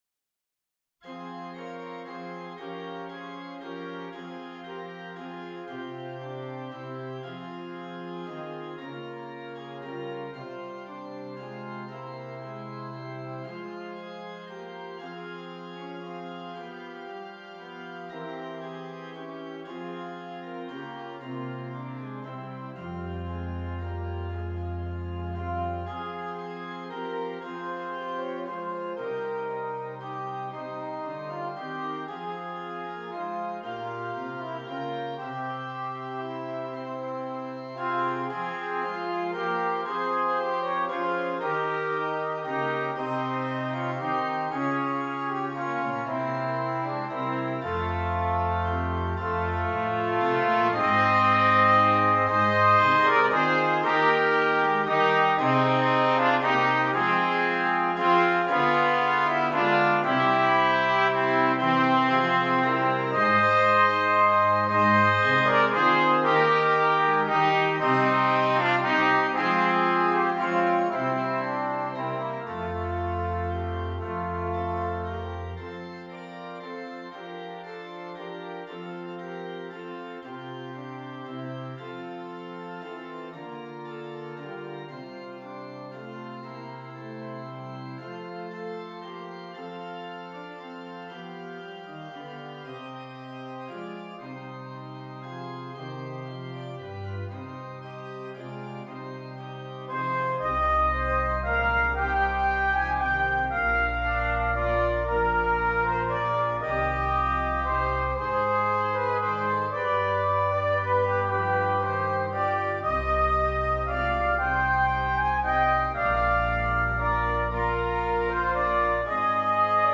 2 Trumpets and Keyboard
Traditional